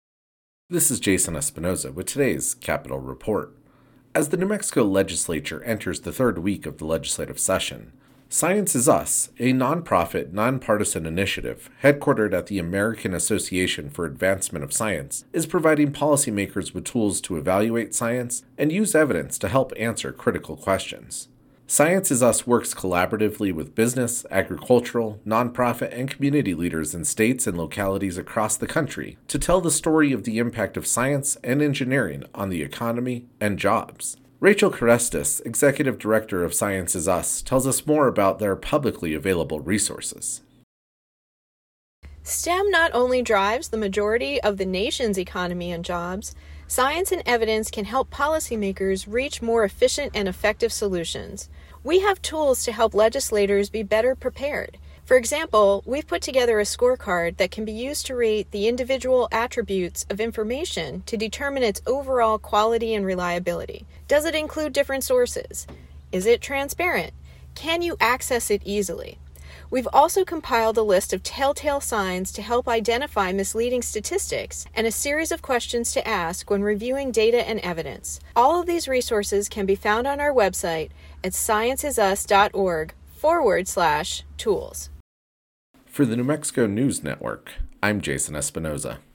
capitol reports